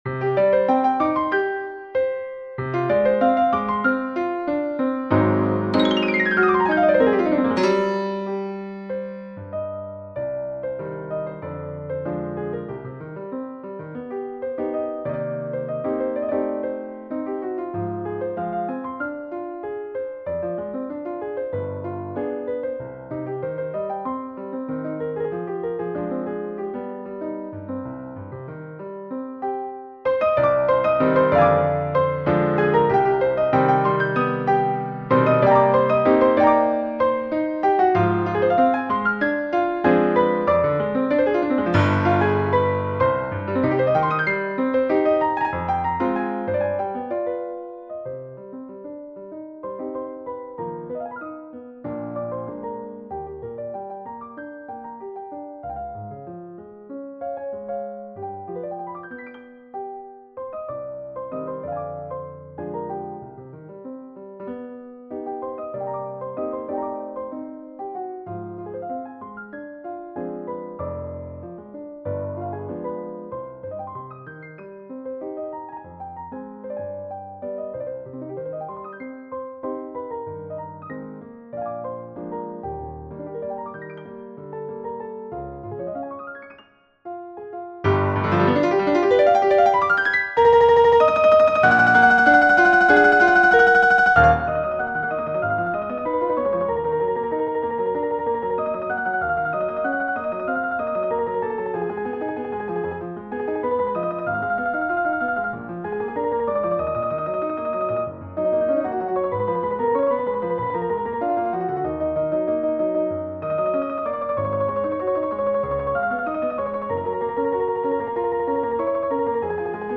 Versión: Arreglo para Piano Solo